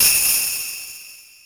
［BGM・SE素材］
龍神の鈴
sozai_harutoki3_se_suzu-a01.mp3